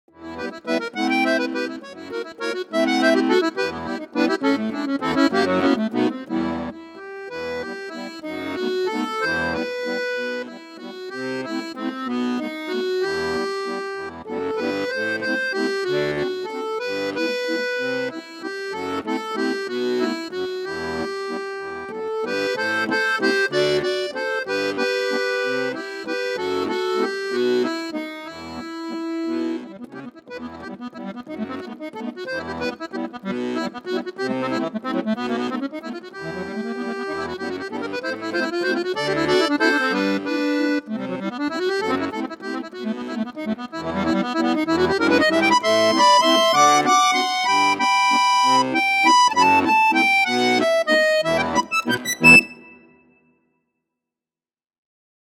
Button Accordion
Solo
Russian concert bayan, fully sampled